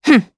Valance-Vox_Attack5_jp.wav